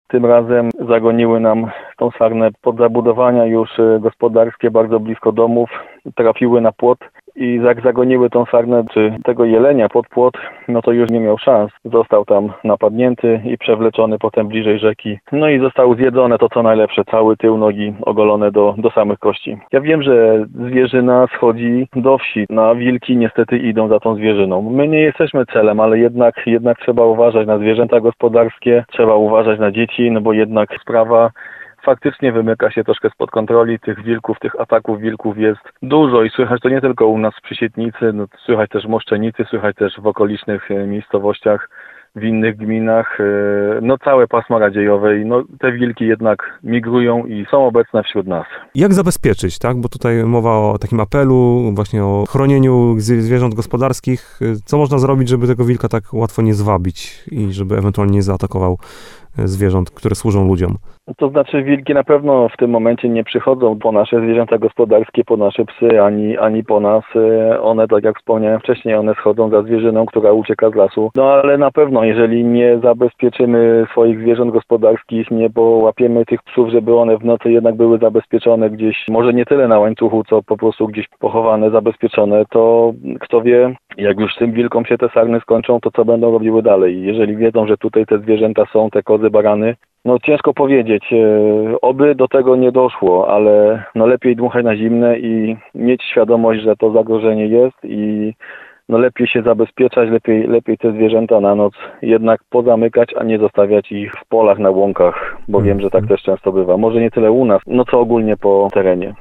Sołtys Przysietnicy Jan Tokarczyk wyjaśnia, że to kolejna taka sytuacja w ostatnich tygodniach.